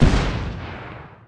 Turretshot Sound Effect
Download a high-quality turretshot sound effect.
turretshot.mp3